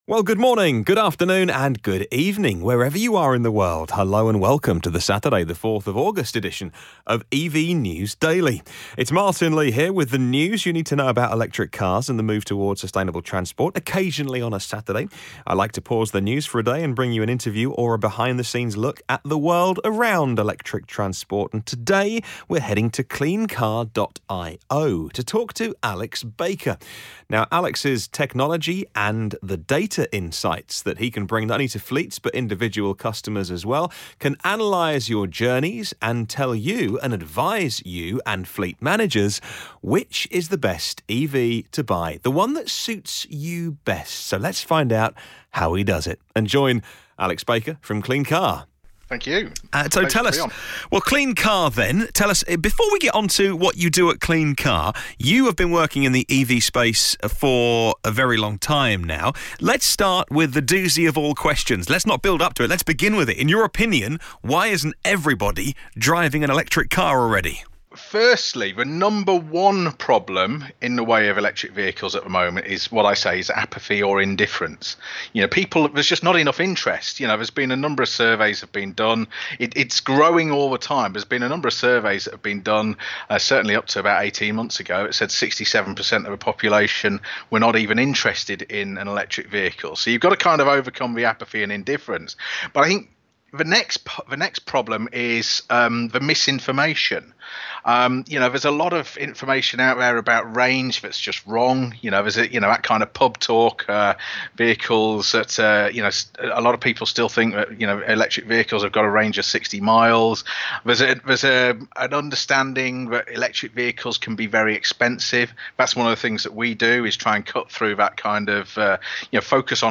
04 August 2018 | Interview: CleanCar Know Which EV You Should Buy